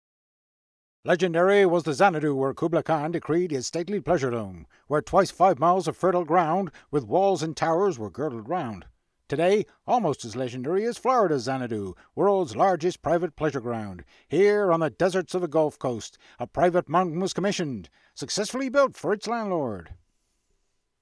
American 1930s Newsreel